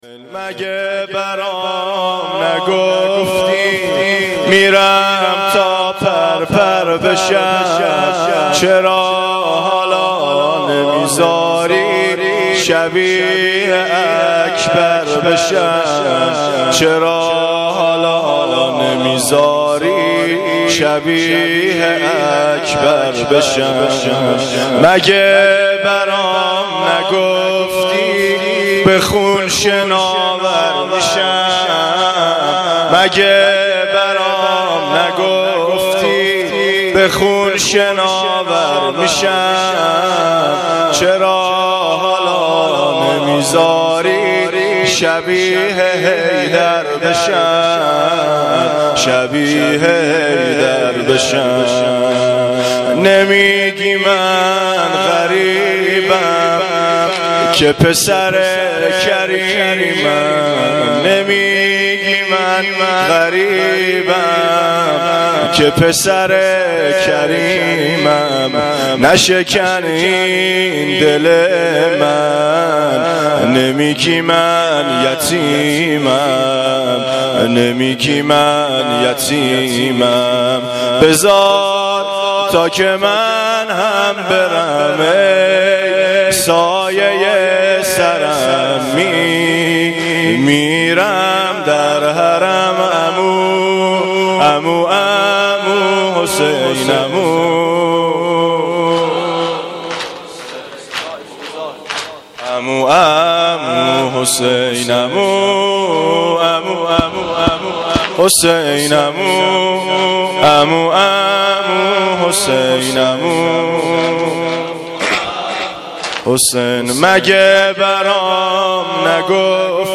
روضه
زمینه.mp3